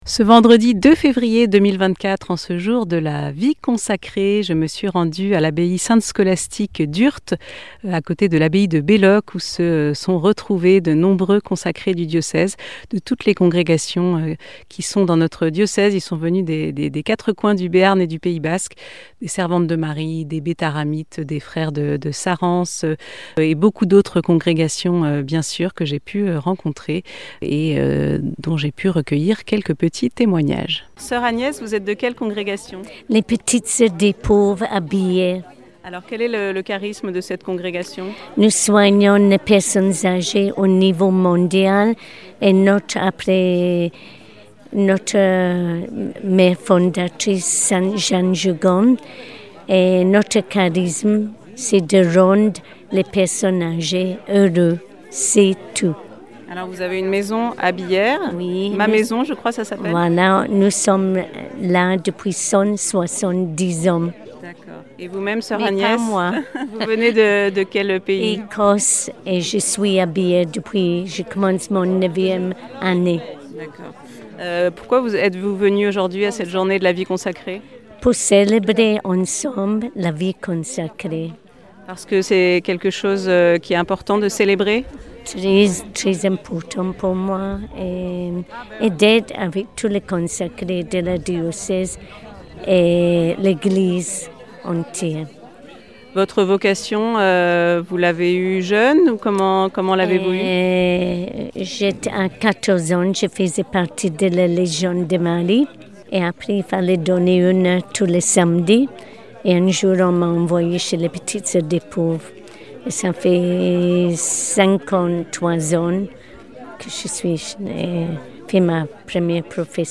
Reportage réalisé à Belloc le 2 février 2024.